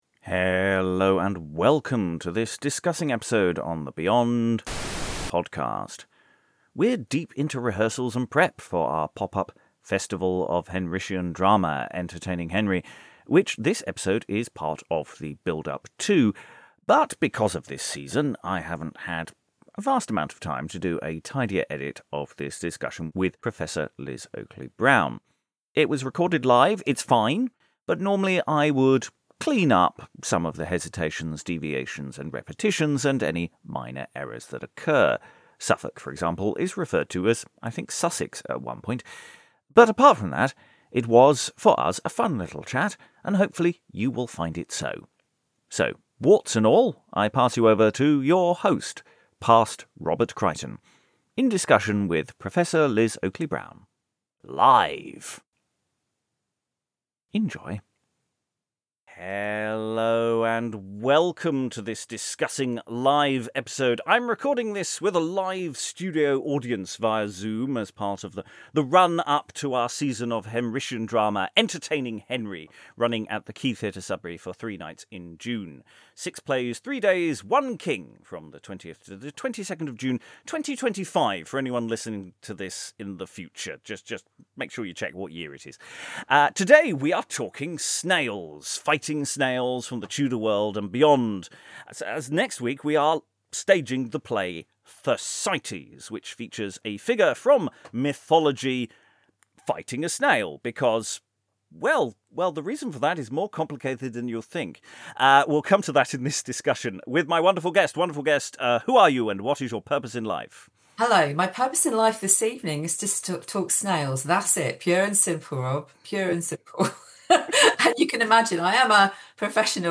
It's here at last, recorded live at The White Bear Theatre during our Winter Revels 2023 on Wednesday 13th December 2023, it's The Tragedy of Cleopatra by Samuel Daniel.